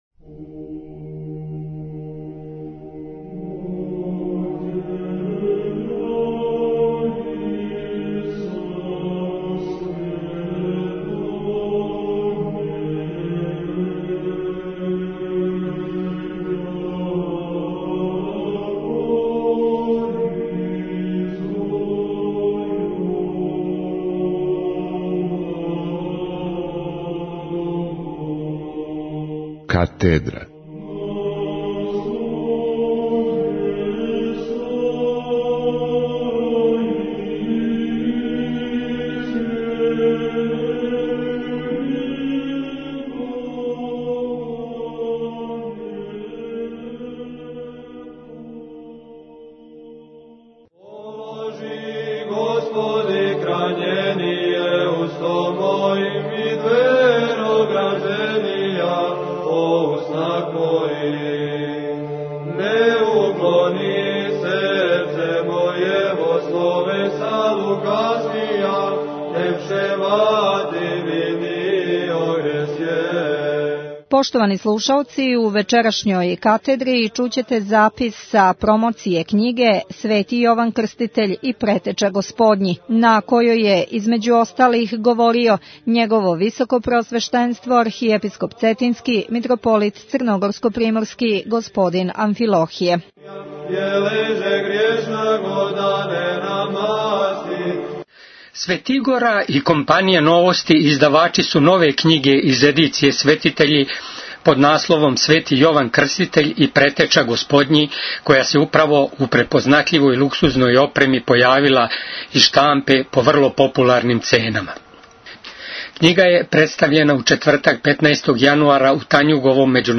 Књига је представљена у четвртак 15. јануара 2009. г. у Танјуговом Међународном прес – центру